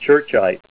Say CHURCHITE-(DY)